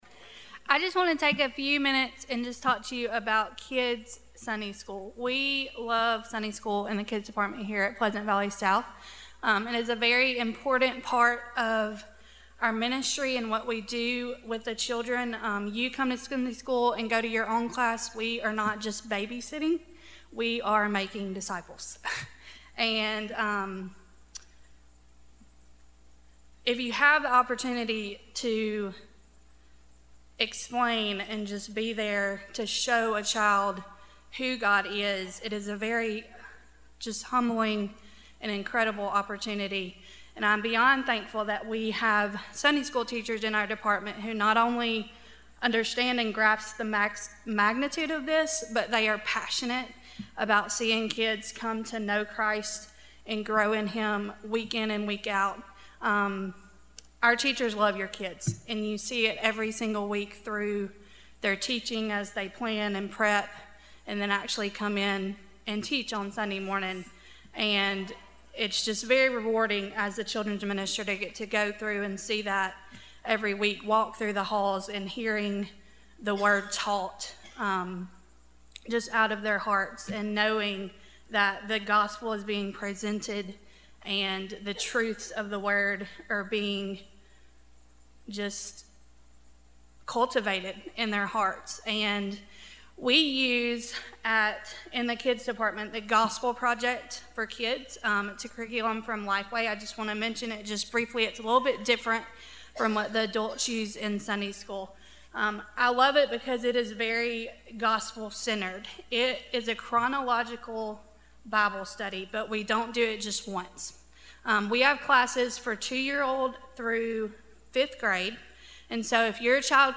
02-24-19am Sermon – The Value of Life